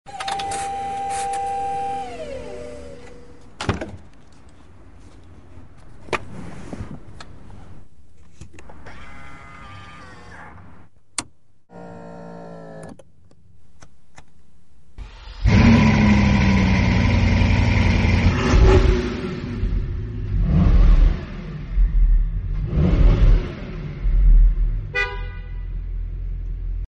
( $7.25M ) ASMR 1 sound effects free download